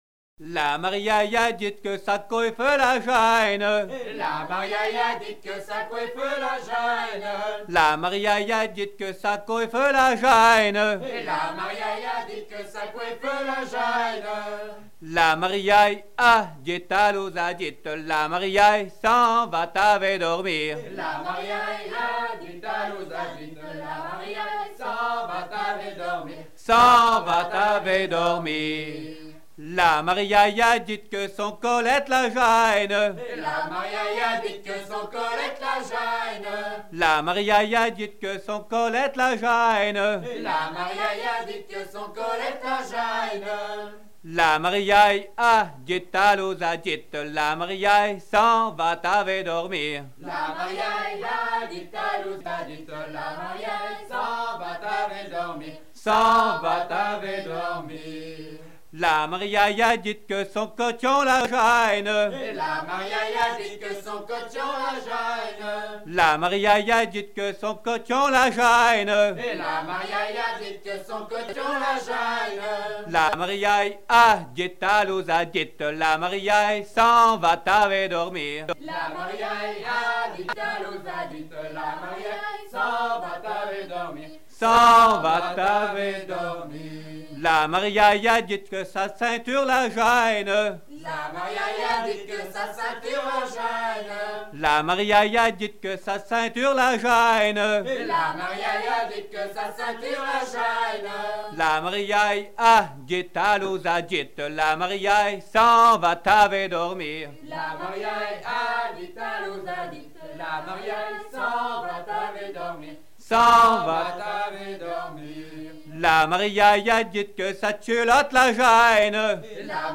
danse : ronde : grand'danse
Genre énumérative